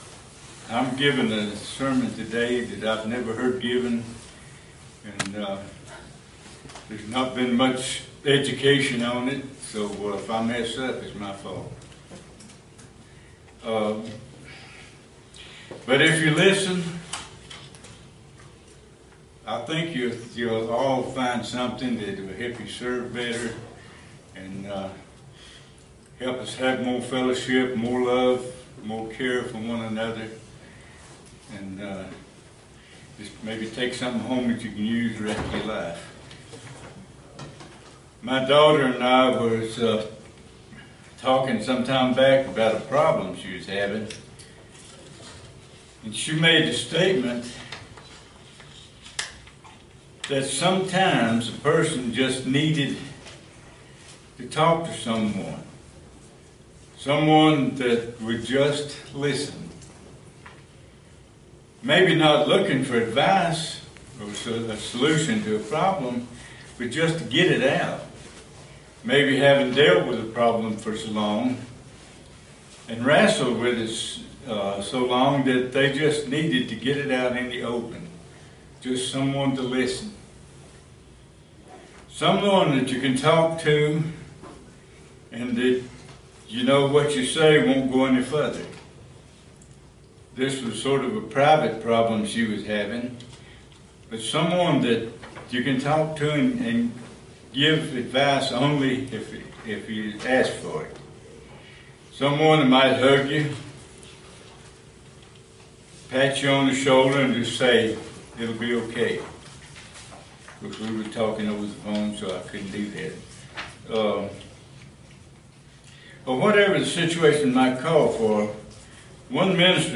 Given in Roanoke, VA